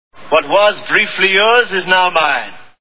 Raiders of the Lost Ark Movie Sound Bites